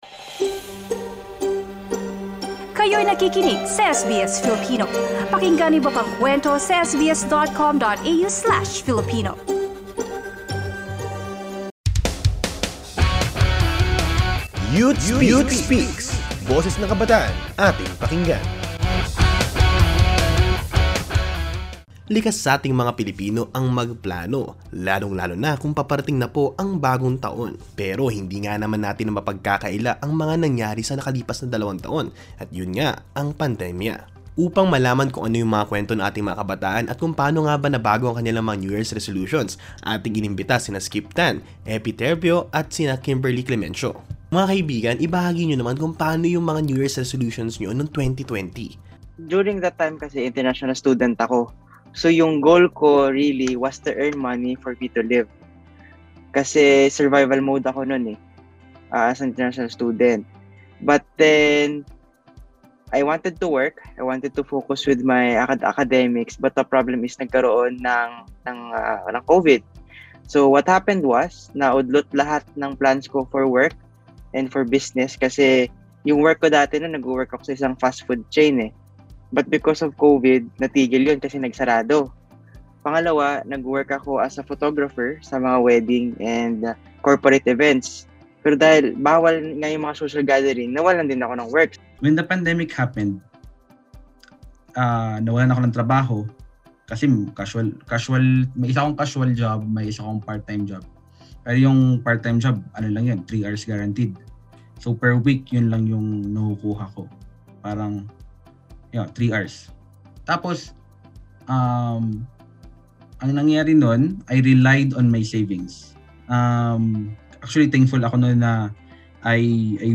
COVID-19 continues to change everything - including the New Year's resolutions people place upon themselves. Young Filipinos share how the virus affects their goals for the coming year.